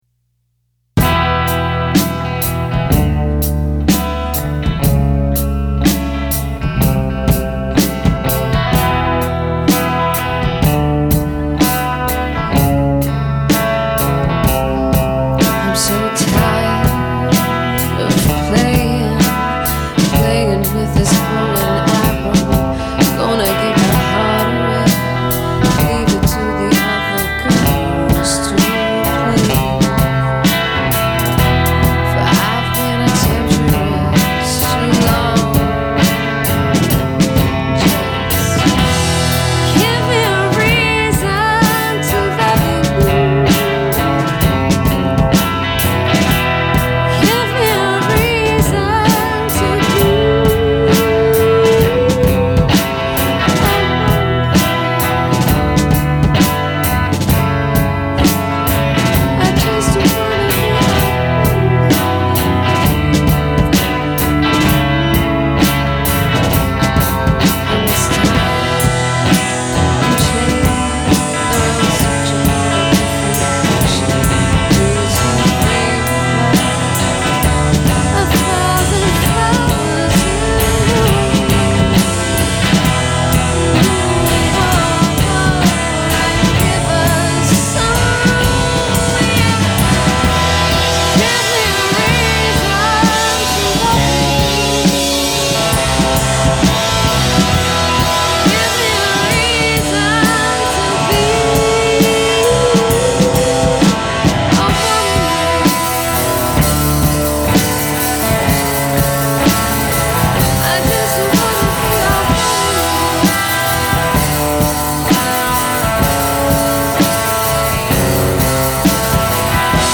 Creephouse Studio Audio Recordings
(wasted version)